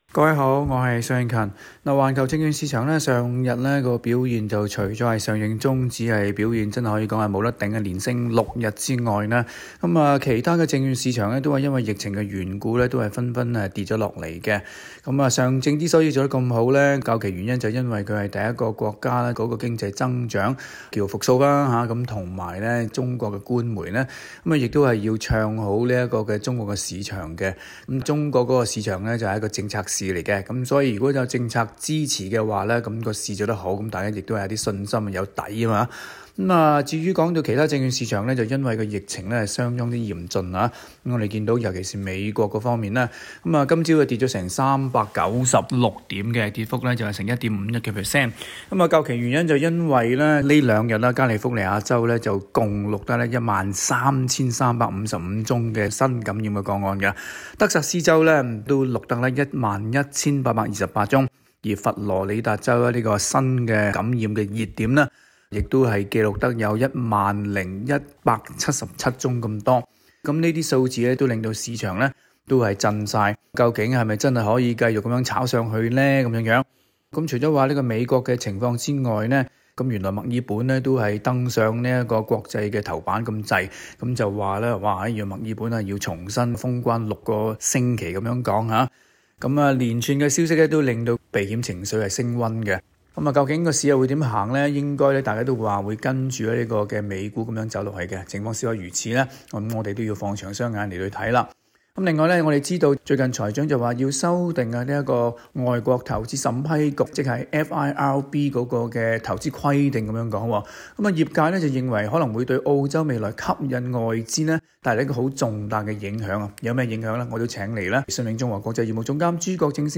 詳情請收聽今期的訪問。